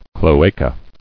[clo·a·ca]